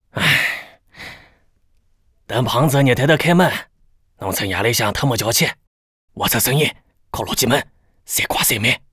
c02_6偷听对话_癞子_7.wav